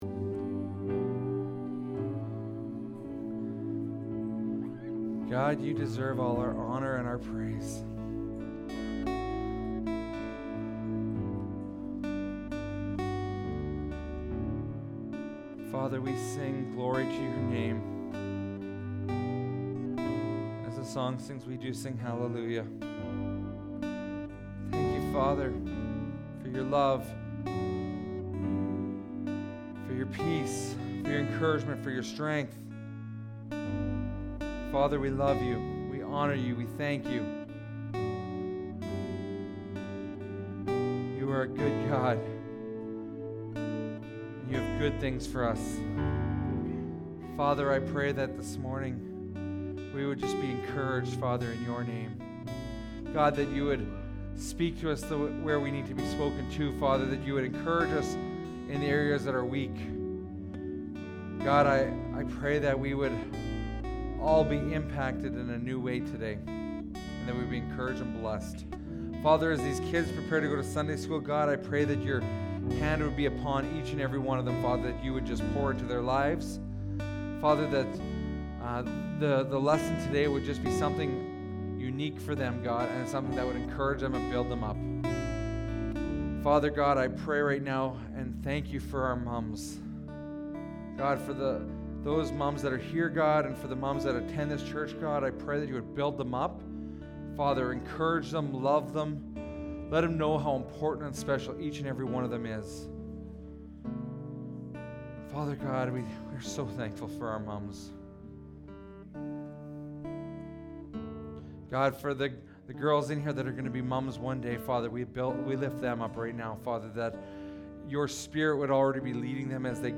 Sermons | Rosetown Community Church